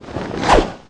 SFX棍棒-抡-带衣服抖动声音效下载
SFX音效